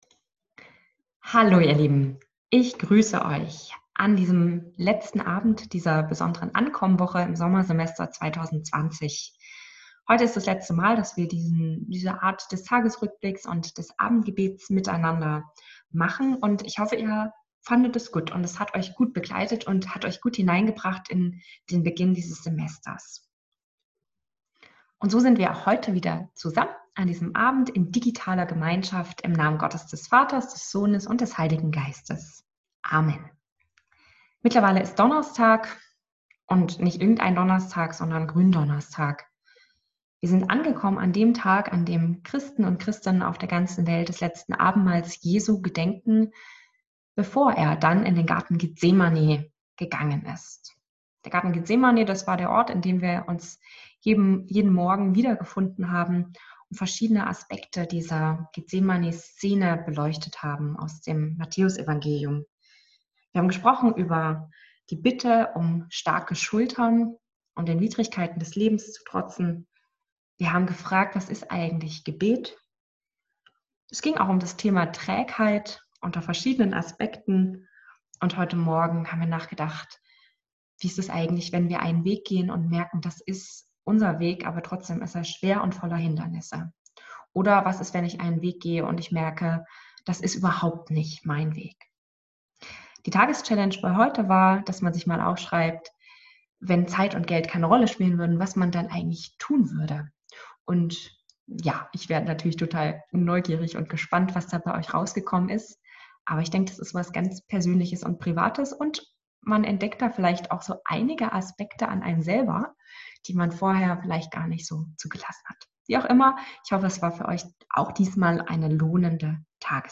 abendgebet_9._april.m4a